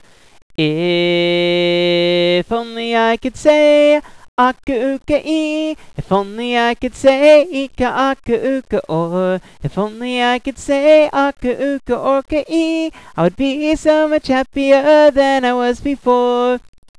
Aka Uka Ee (song)